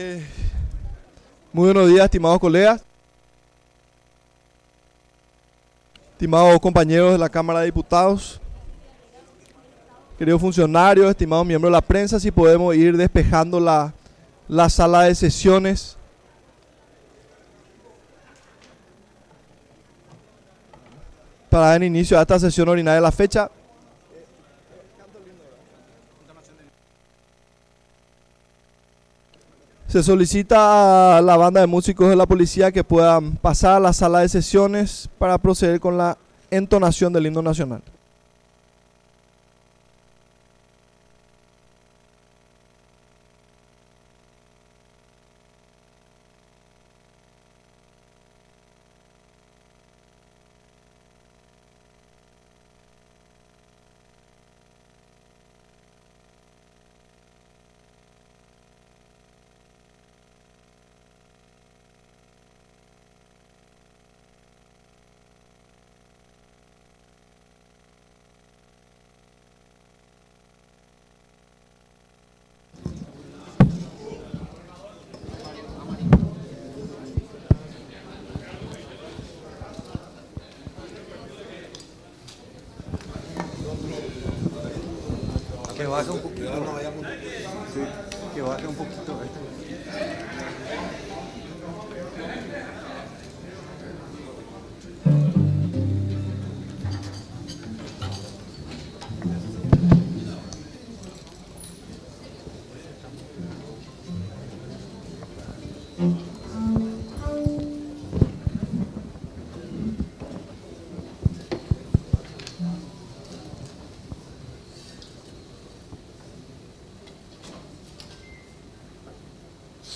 Sesión Ordinaria, 7 de abril de 2026